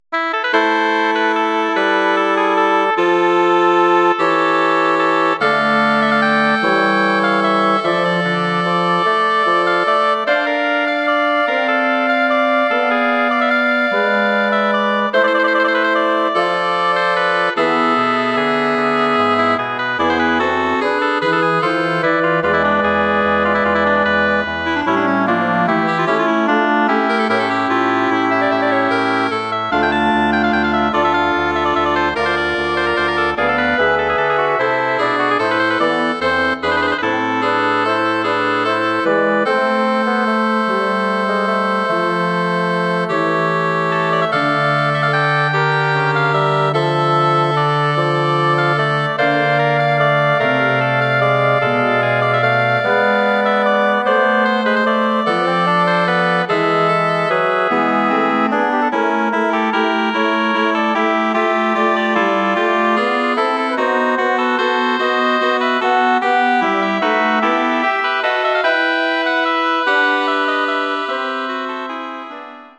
Bearbeitung für Bläserquintett
Besetzung: Flöte, Oboe, Klarinette, Horn, Fagott
Arrangement for wind quintet
Instrumentation: flute, oboe, clarinet, horn, bassoon